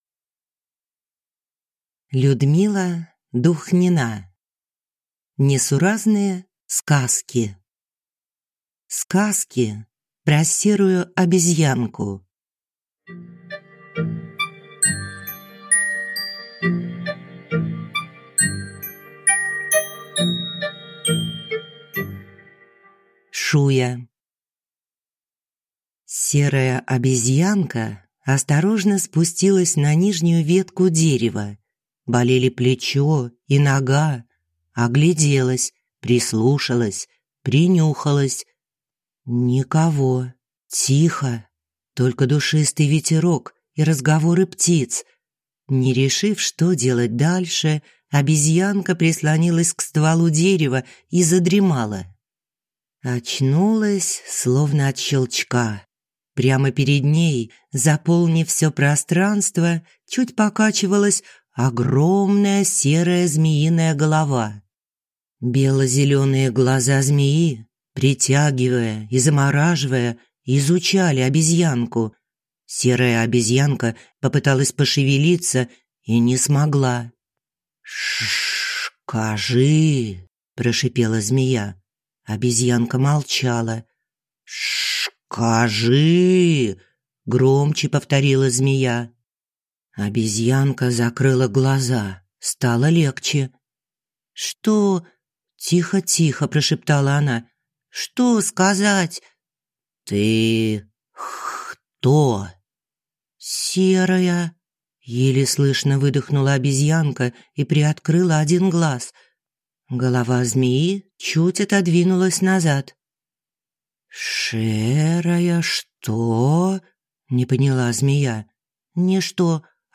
Аудиокнига Несуразные сказки | Библиотека аудиокниг